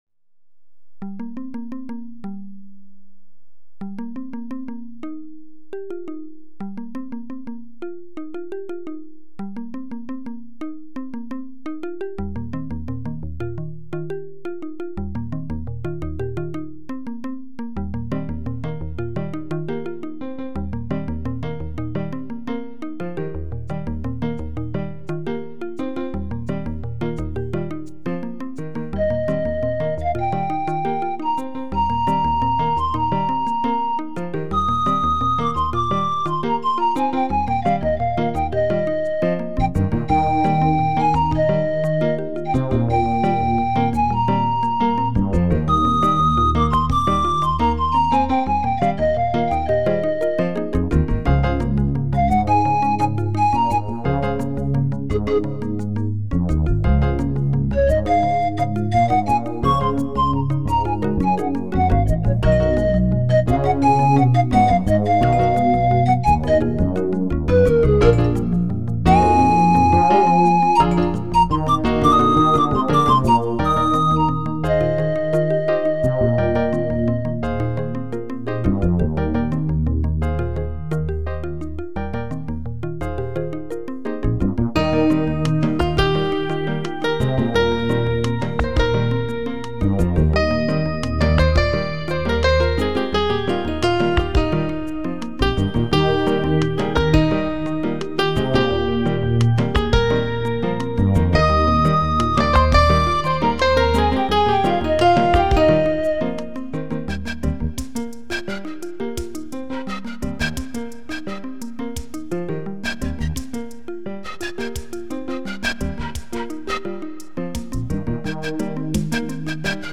The pieces in this collection are among my first real attempts at composing music for synthesizers (mainly a Korg M1 and a Yamaha DX7).
I still don't know what they're about, but now I feel that there is a certain cinematic aspect to some of them.